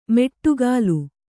♪ meṭṭugālu